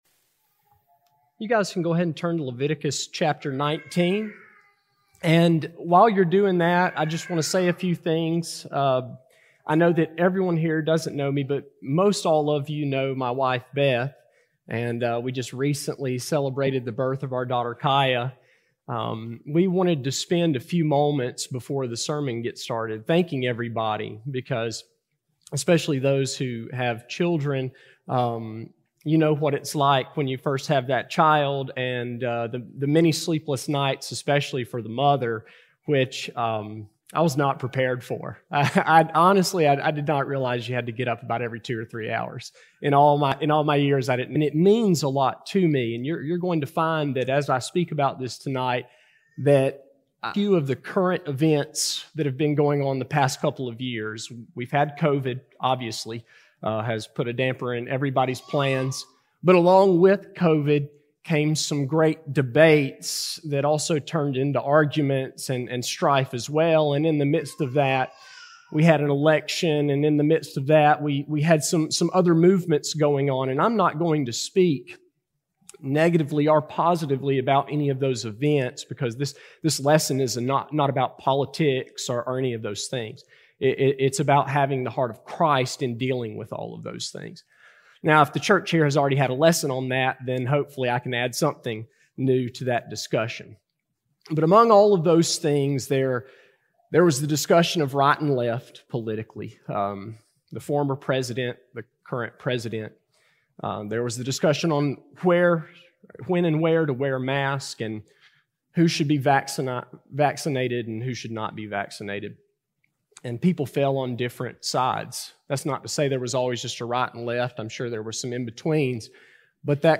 A sermon recording given by guest speaker